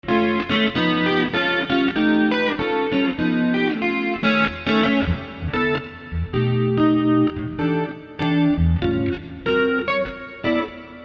Jazz
Nor do we know what kinds of guitars were used…We only know that the guitar tracks were recorded direct using only a SansAmp Classic and standard studio outboard EQ and reverb equipment.
SA-jazz.mp3